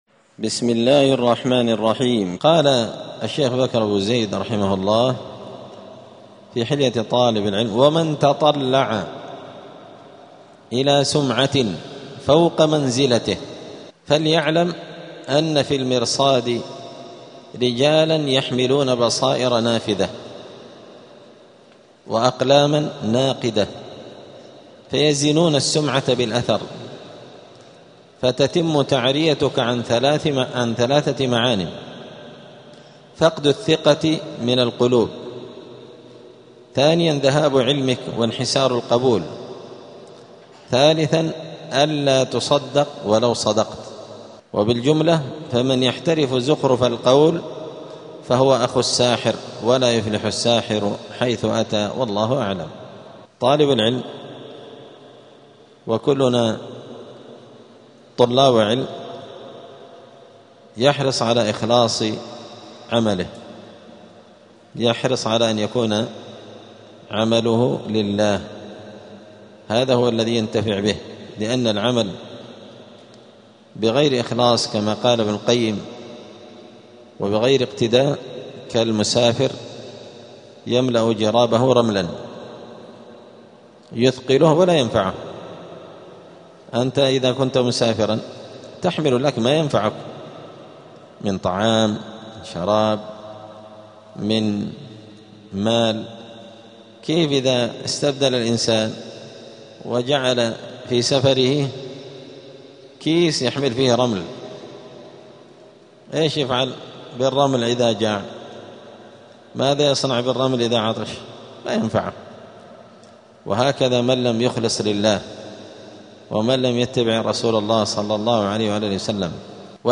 *الدرس الخامس والسبعون (75) فصل آداب الطالب في حياته العلمية {من يحترف زخرف القول، فهو أخو الساحر}.*